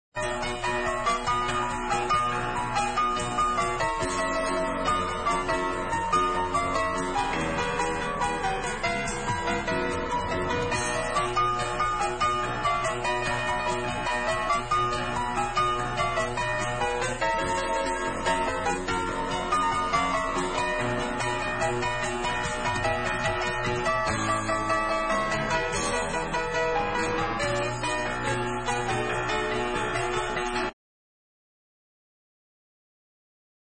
Recorded at Ramport Studios and Wessex Studios, London.
Lead Vocals and all Keyboards
Back-up vocals
Drums and Percussions.
Bass
Guitars.